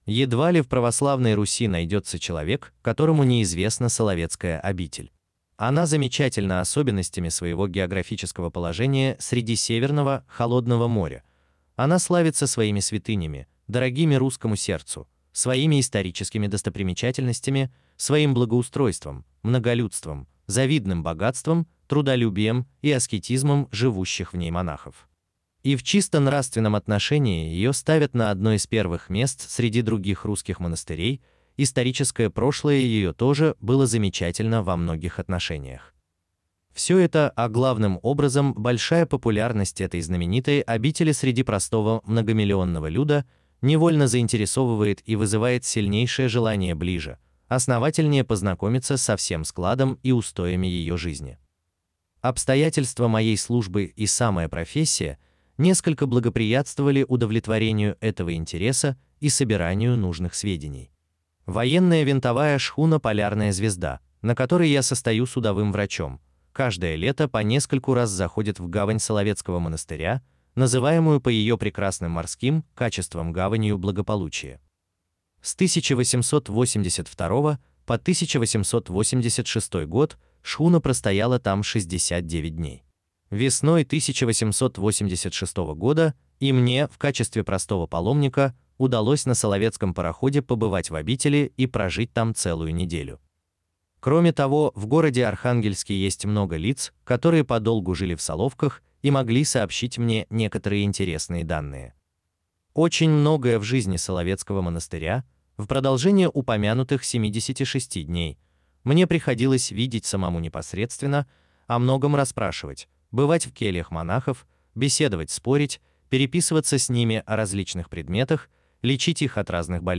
Аудиокнига Соловки. Внутренний уклад и внешняя жизнь Соловецкого монастыря | Библиотека аудиокниг